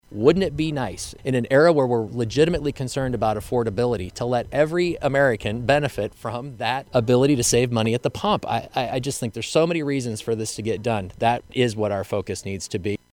HE SAYS NOT ONLY CORN PRODUCERS WOULD BENEFIT, BUT DRIVERS AS WELL, SINCE E15 IS 10 TO 15 CENTS PER GALLON CHEAPER THAN E10 FUEL: